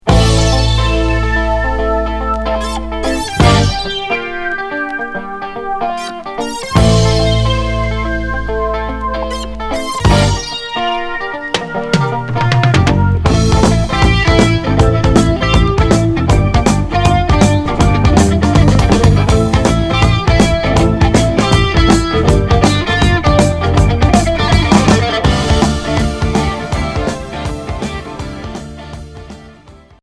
Hard progressif Deuxième 45t retour à l'accueil